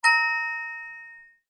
a sound to represent the points that are awarded in the old I SPY books for spying various items, using a toy xylophone.
happy-ding.mp3